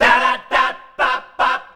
DARABAB C.wav